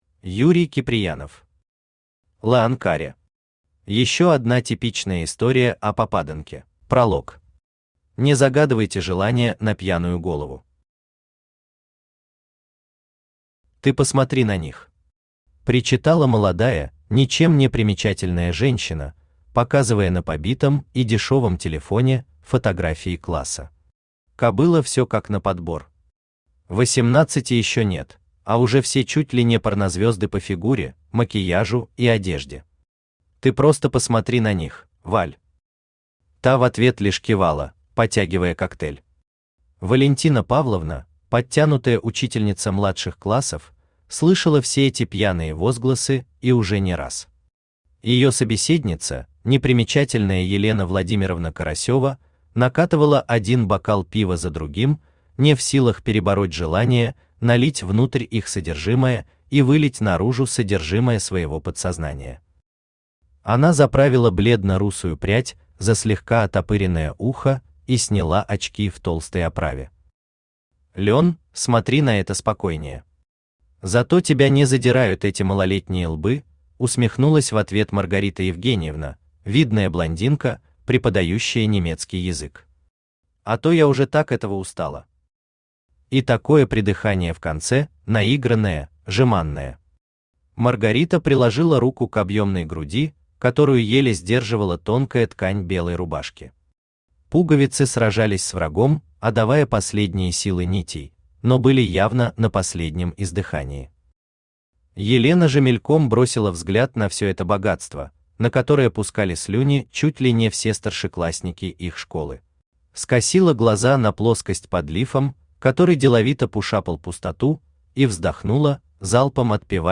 Аудиокнига Лэанкаре. Еще одна типичная история о попаданке | Библиотека аудиокниг
Еще одна типичная история о попаданке Автор Юрий Михайлович Киприянов Читает аудиокнигу Авточтец ЛитРес.